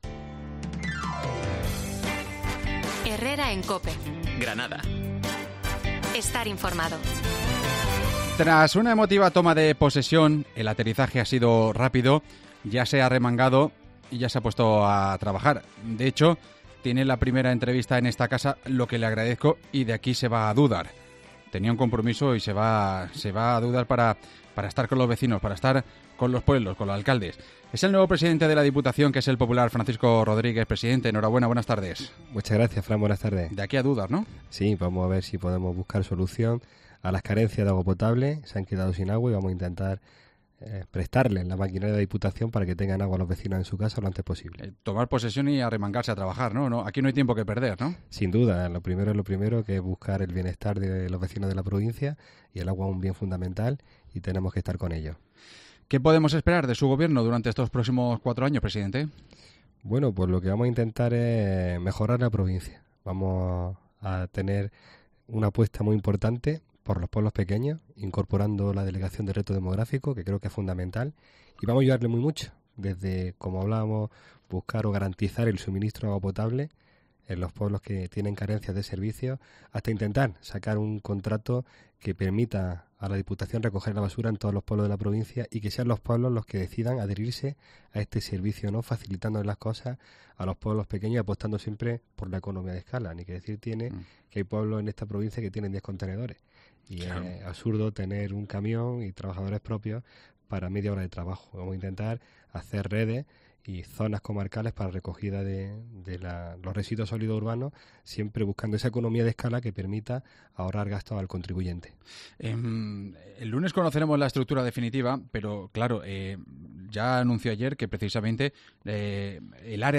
AUDIO: El nuevo presidente de la Diputación de Granada, Francisco Rodríguez, concede su primera entrevista a COPE tras su investidura